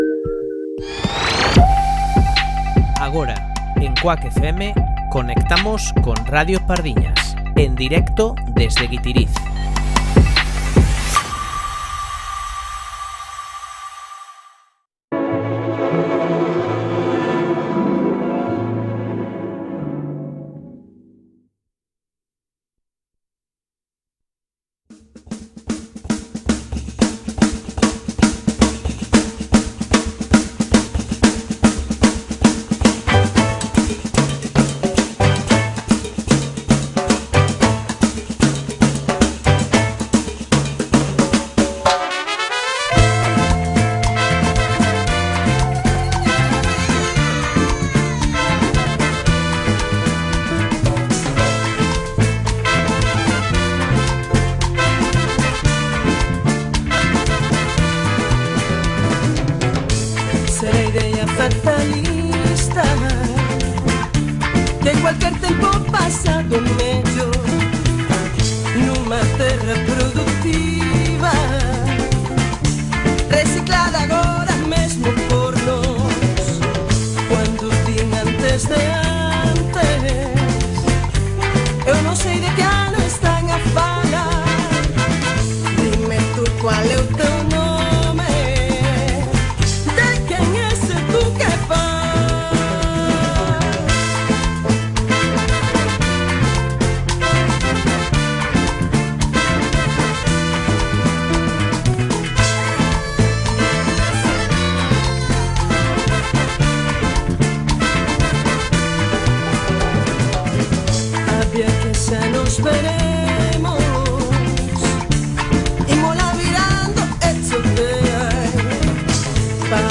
Conexión en directo con Radio Pardiñas, a emisión radiofónica desde o Festival de Pardiñas (Pardiñas, Guitiriz, Lugo).